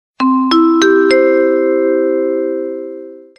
Звуки объявления в аэропорту
Звук оповещения диспетчера перед объявлением в аэропорту